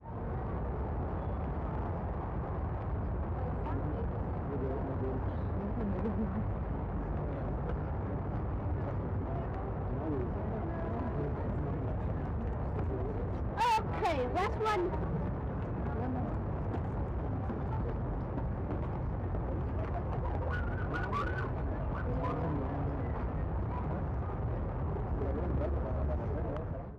Audio of Mimicking Dog’s Perspective
That’s my version of what a dog might hear, which is set in the range of 40-20kHz and made in far-away-like, mimicking that dog can perceive sound from far away. Also, I added a distortion plug-in to simulate the effect of a deaf aid.
RH_Dog_Perspective.wav